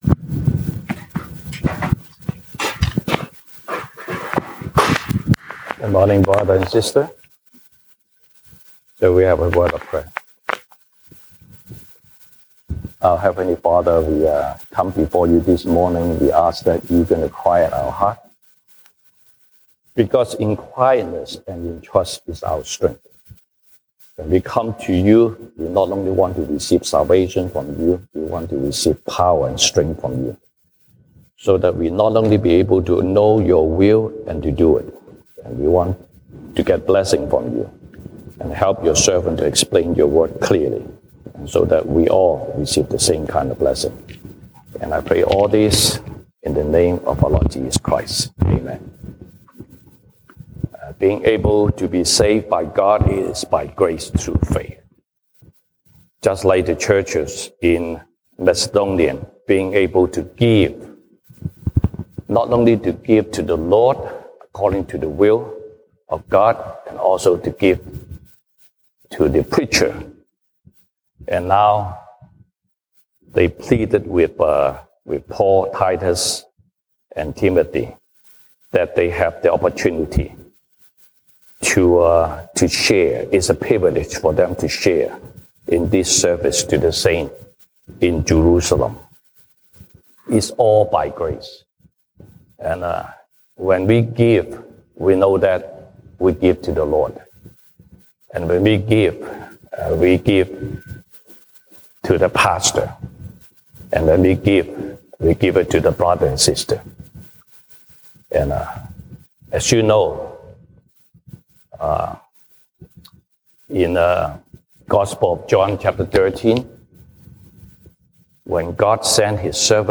西堂證道 (英語) Sunday Service English: The Joy and the Grace of Giving
Passage: 歌林多後書 2 Corinthians 8:5-24 Service Type: 西堂證道 (英語) Sunday Service English